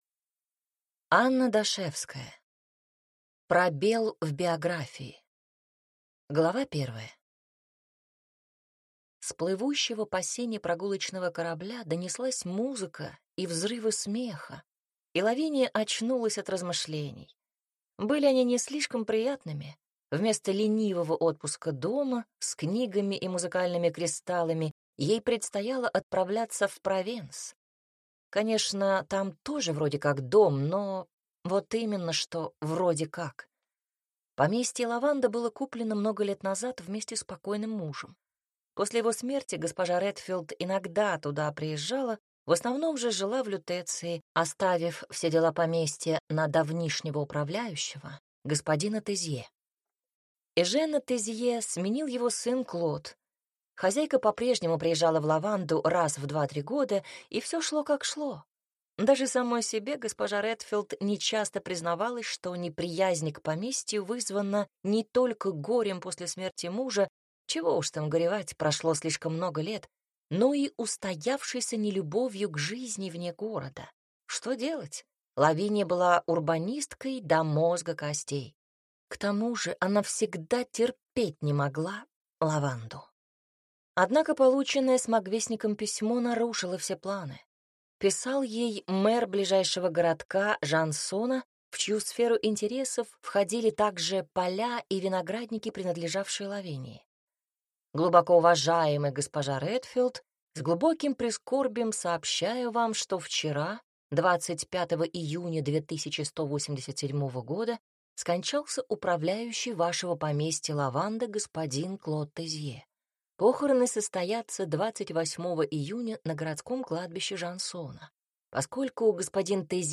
Аудиокнига Пробел в биографии | Библиотека аудиокниг